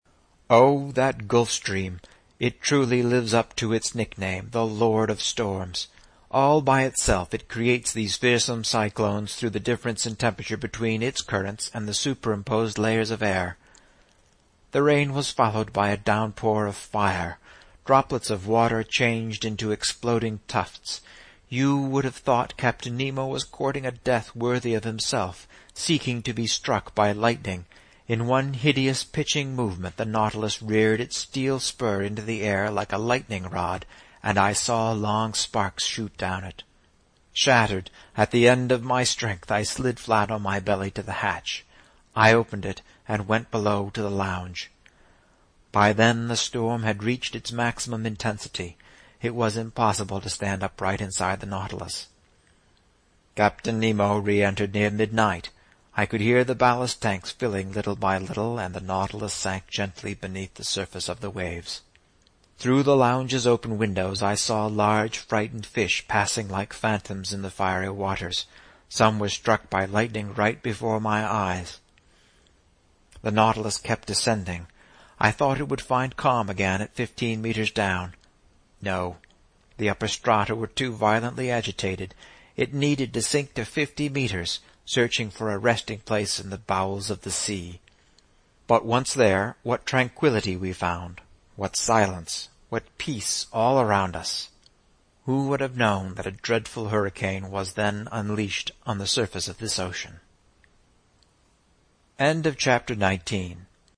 英语听书《海底两万里》第525期 第32章 海湾暖流(16) 听力文件下载—在线英语听力室
在线英语听力室英语听书《海底两万里》第525期 第32章 海湾暖流(16)的听力文件下载,《海底两万里》中英双语有声读物附MP3下载